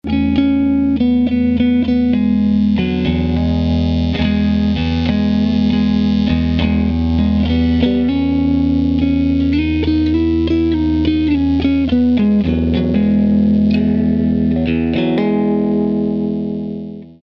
Gitarrenbrummen im Tonstudio
Ich habe vor einigen Monaten ein Tonstudio bezogen und bemerkt, dass es wohl ein relativ starkes elektromagnetisches Feld geben muss, welches in Gitarrentonabnehmer, vor allem Singlecoils, einstreut und sich in einem Brummen und Sirren aus dem Verstärker äußert. Hier ist eigentlich das ganze Spektrum von 50 Hz bis 20 kHz relativ gleichmäßig vertreten.